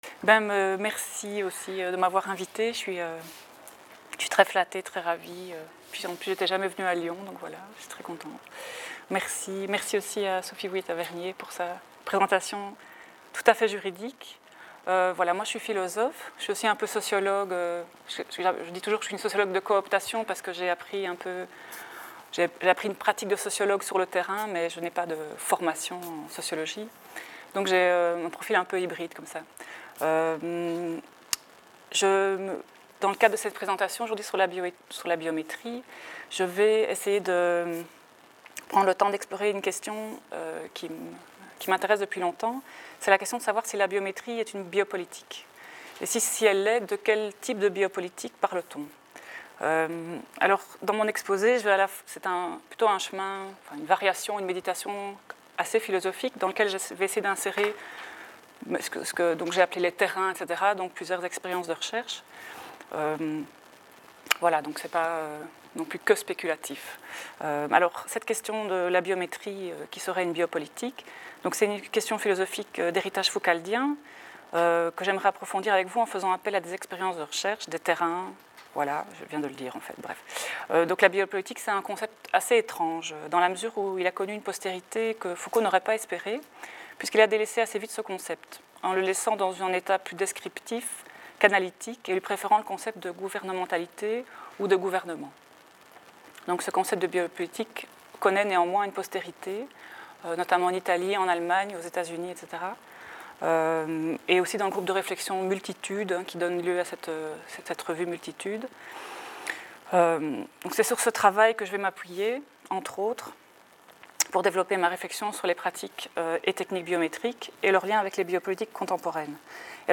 Biopolitiques postmodernes et biométrie Séminaire interdisciplinaire organisé à l’Institut Français de l’Éducation de l’École Normale Supérieure de Lyon.